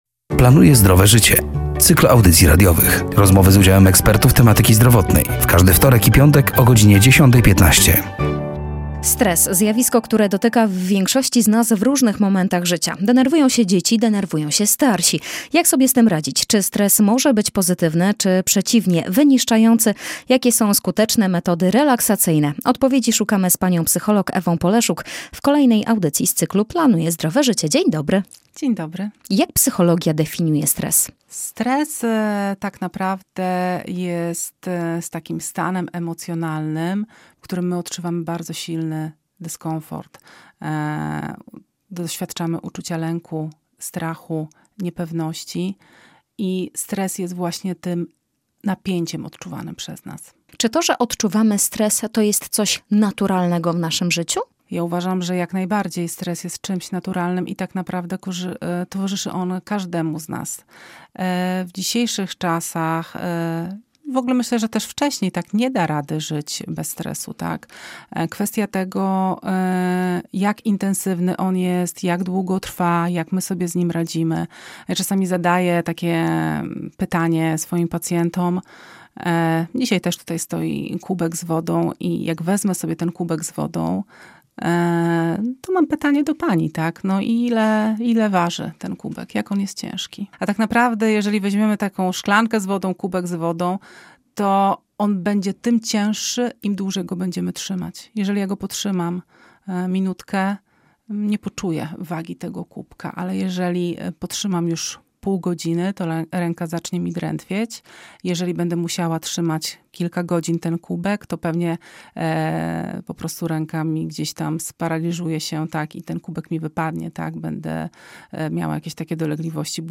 ,,Planuję Zdrowe Życie”, to cykl audycji radiowych poświęconych upowszechnianiu wiedzy z zakresu zdrowego stylu życia, promujących zdrowie i edukację zdrowotną. Rozmowy z udziałem ekspertów tematyki zdrowotnej.